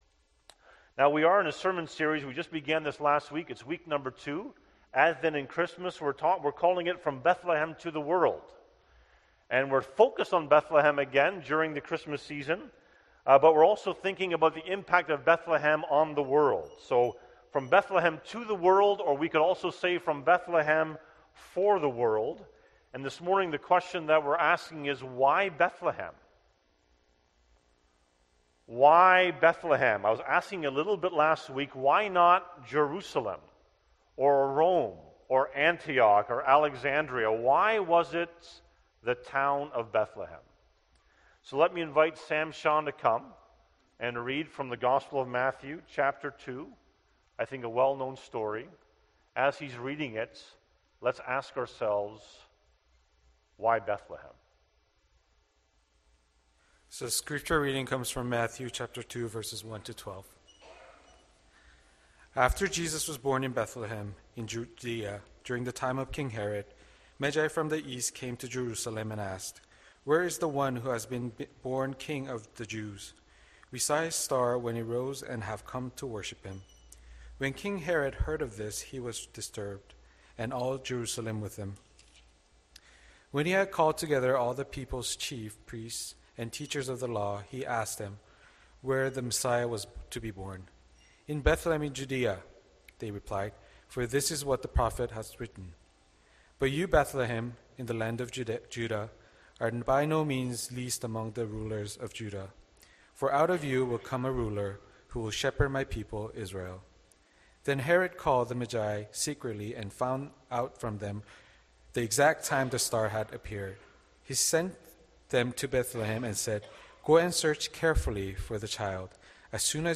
Sermons | Nelson Avenue Community Church